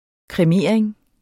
Udtale [ kʁεˈmeˀɐ̯eŋ ]